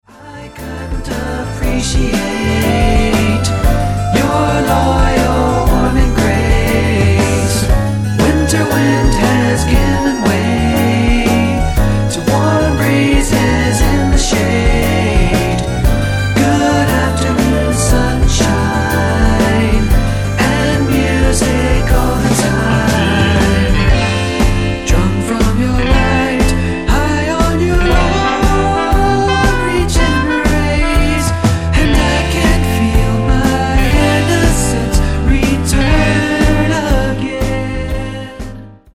王道サニーポップ